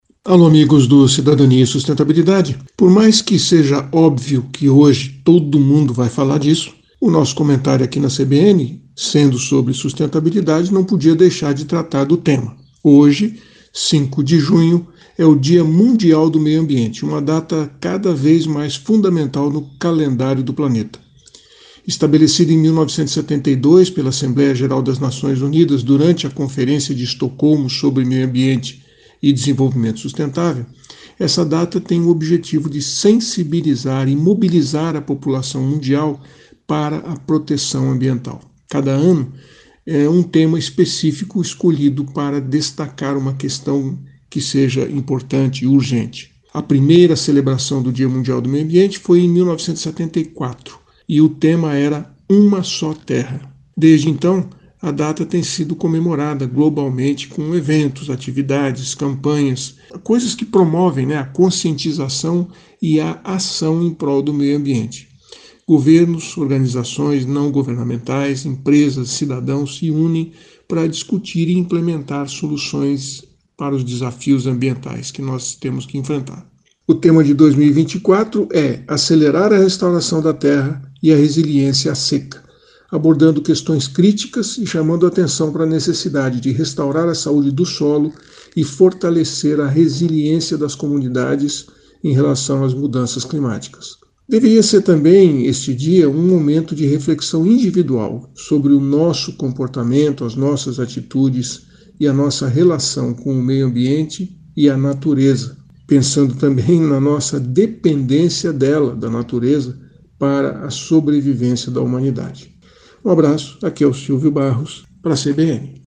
CBN Cidadania e Sustentabilidade, com Silvio Barros, fala sobre atitudes sustentáveis feitos por instituições e pessoas.